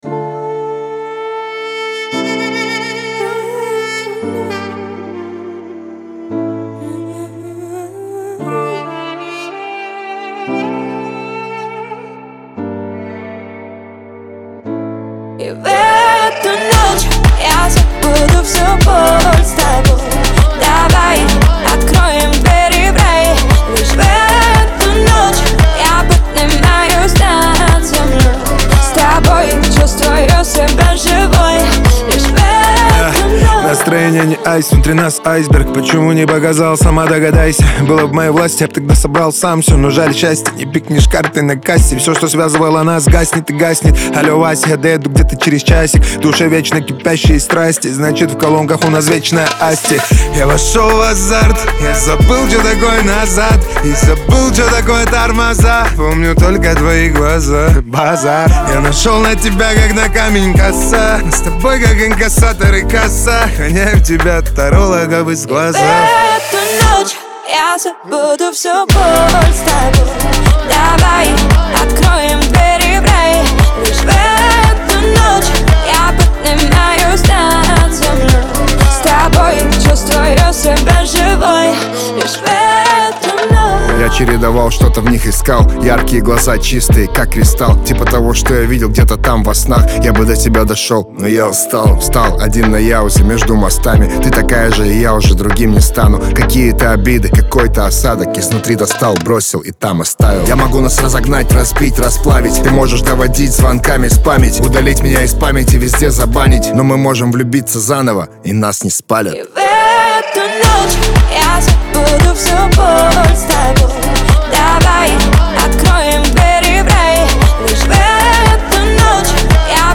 ХАУС-РЭП
эстрада
дуэт